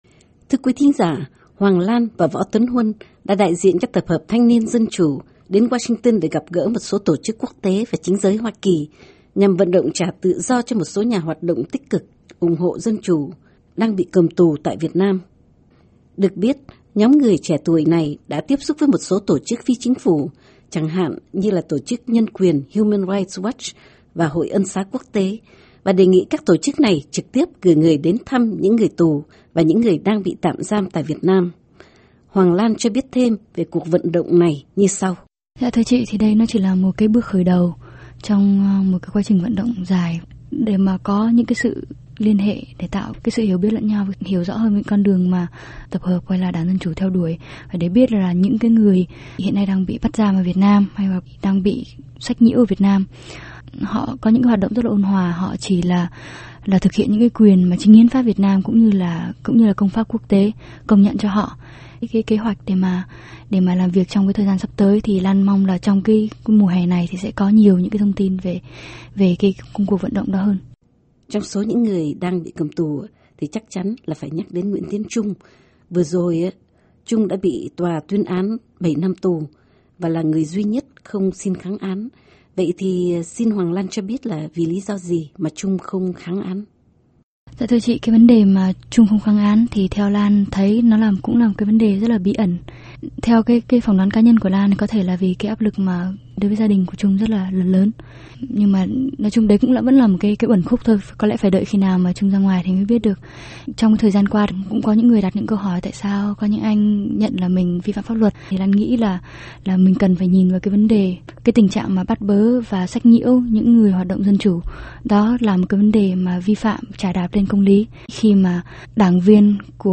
interview_thtndc_25may10_voa.Mp3